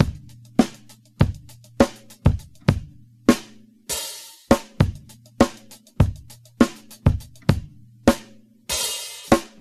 100 Bpm Drum Beat C# Key.wav
Free drum beat - kick tuned to the C# note. Loudest frequency: 1589Hz
100-bpm-drum-beat-c-sharp-key-fW3.ogg